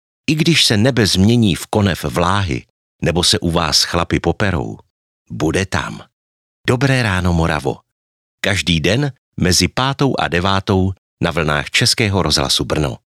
Profesionální český voiceover
• Nabízím profesionální, příjemný mužský hlas ošlehaný téměř 30 lety zkušeností u mikrofonů v rádiích i studiích.
Profesionální, zralý mužský hlas pro váš voiceover, reklamu, hlasovou aplikaci nebo dabing